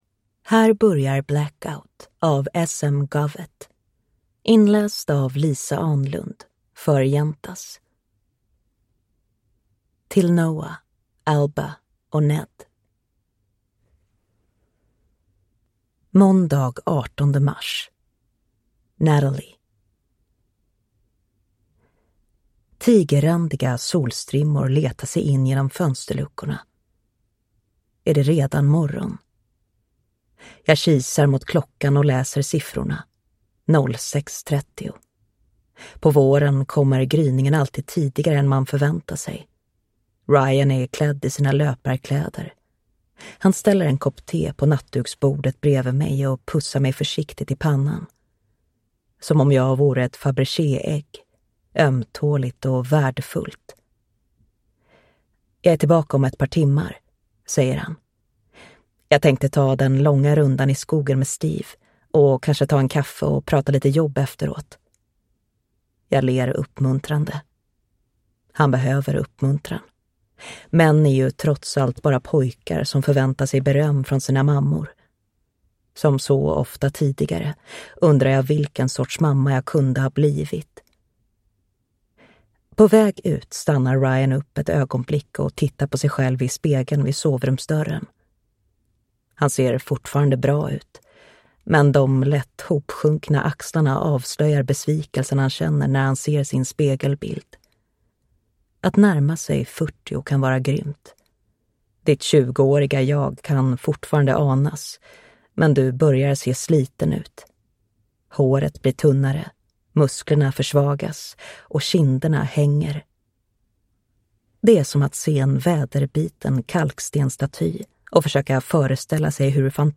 Blackout (ljudbok) av S. M. Govett